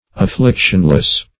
Afflictionless \Af*flic"tion*less\, a. Free from affliction.